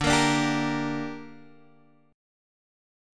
D Suspended 4th Sharp 5th
Dsus4#5 chord {x x 0 3 3 3} chord